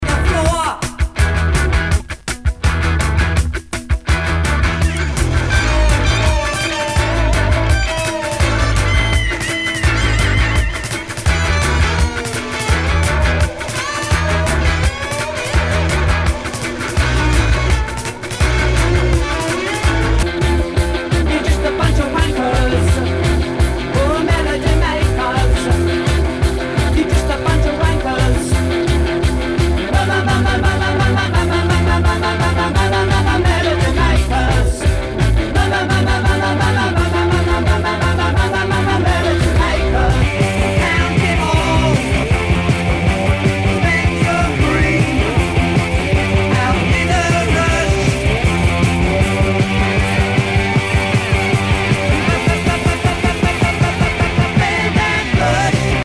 ROCK/POPS/INDIE
ナイス！インディーロック！